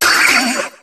Cri de Grindur dans Pokémon HOME.